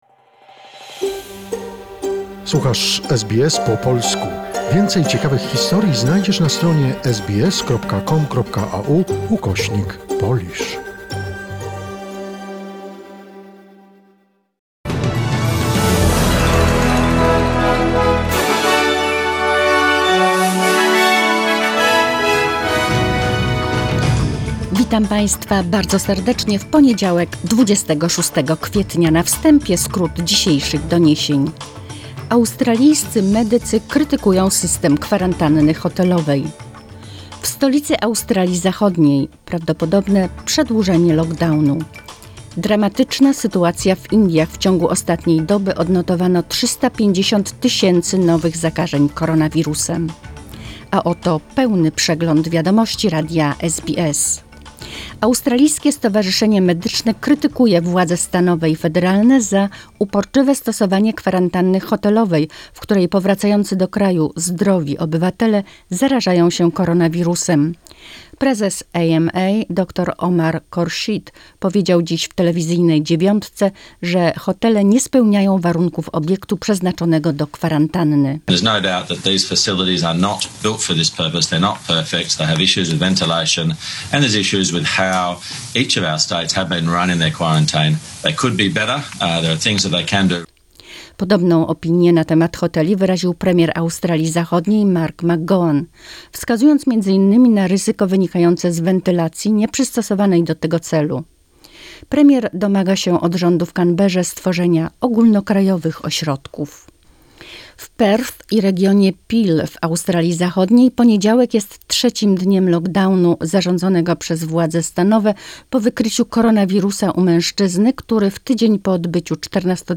Wiadomości SBS, 26 kwietnia 2021 r.